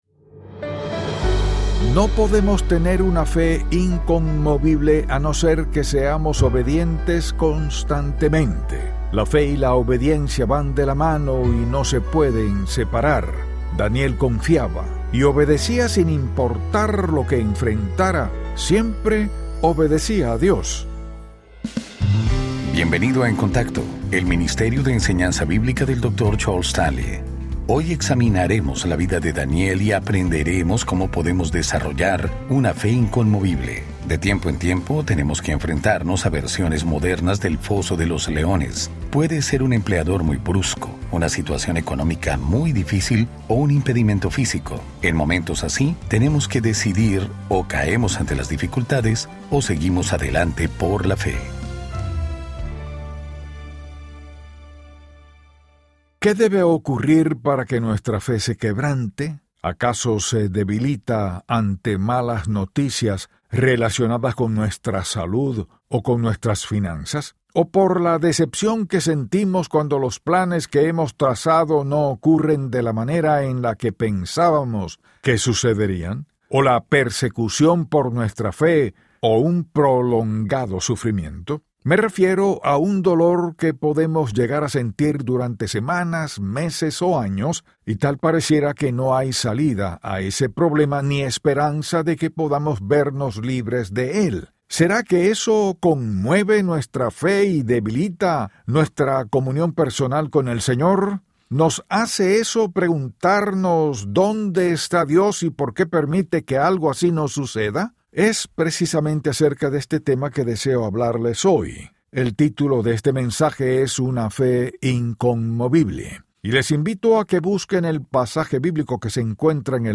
Dr. Charles Stanley y el programa diario de radio In Touch Ministries.